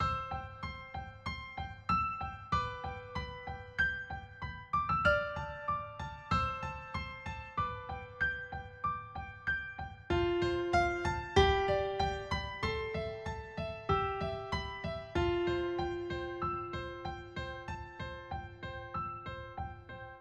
悲伤的罗菲爵士乐合直立式钢琴
标签： 95 bpm LoFi Loops Piano Loops 3.40 MB wav Key : Gm Logic Pro
声道立体声